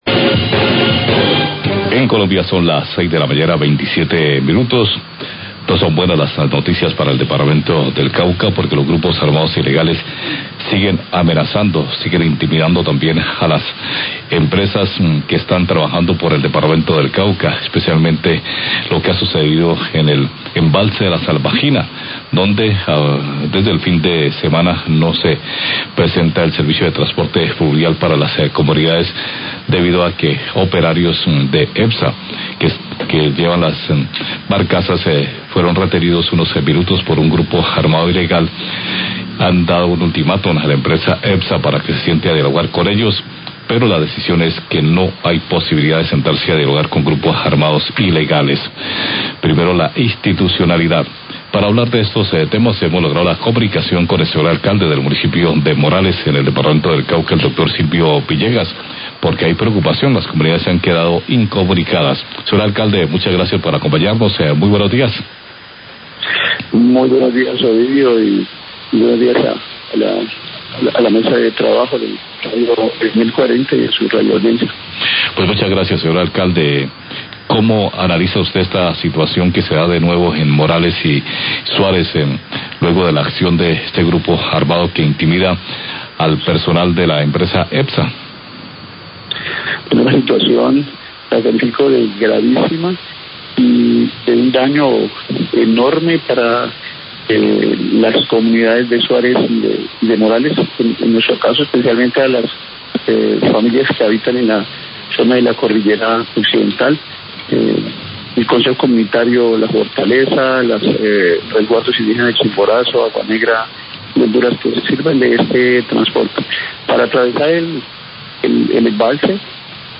Radio
La situación es gravísima es un daño para las comunidades de la zona de la Cordillera Occidental de Suárez y Morales que tienen que atravesar el embalse debido a la suspensión del transporte fluvial en el represa La Salvajina por problemas de orden público. Declaraciones del Alcalde de Morales, Silvio Villegas.